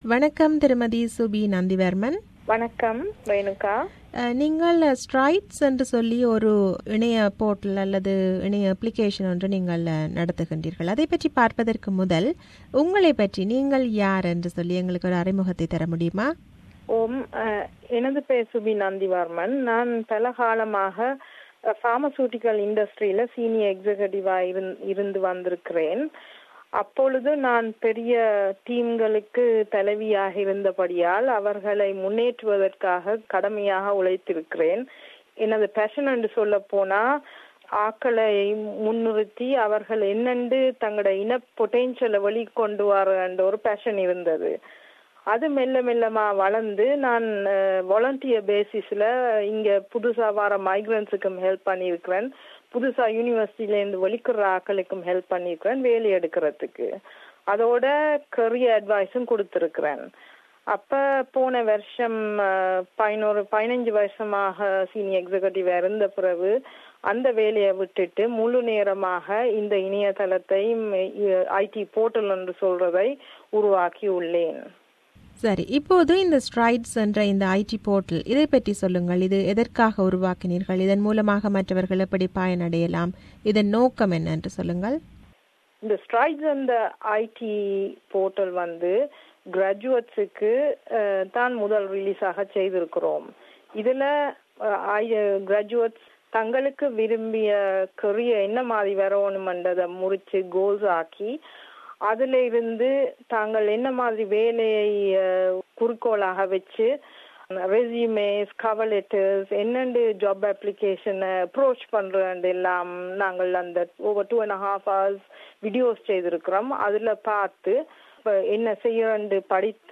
This is an interview with her.